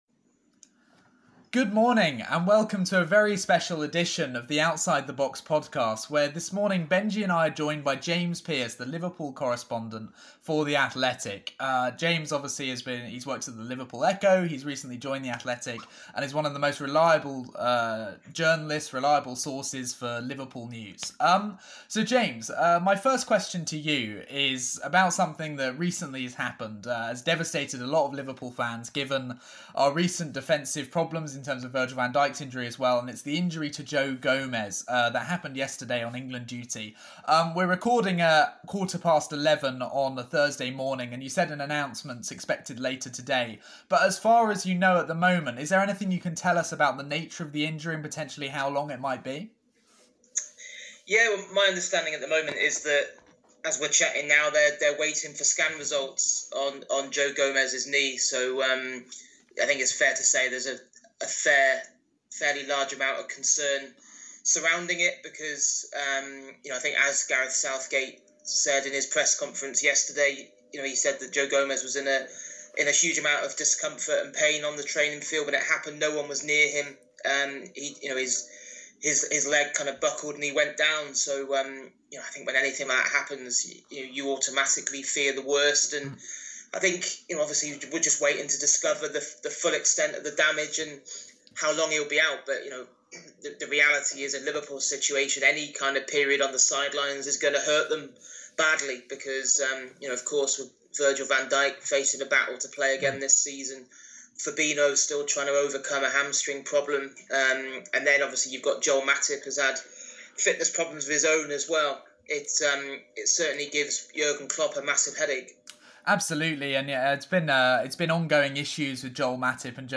Outside The Box: Interview